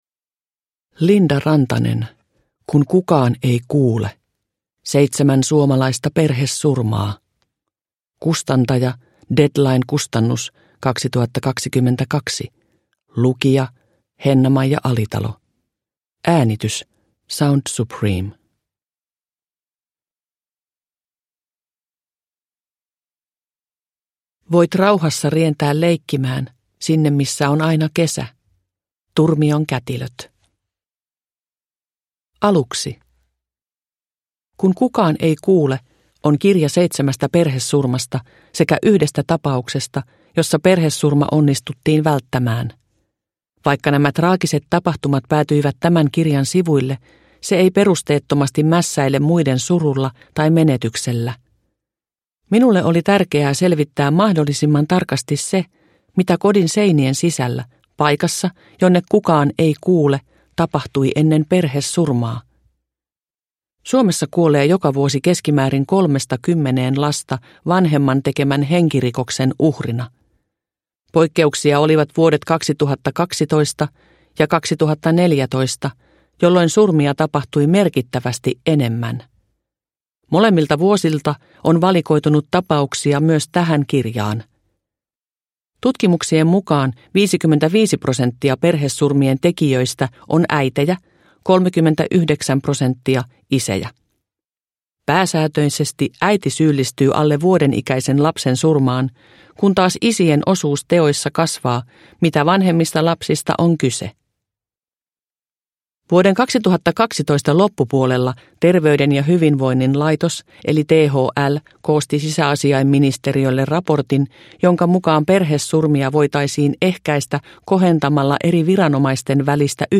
Kun kukaan ei kuule (ljudbok) av Linda Rantanen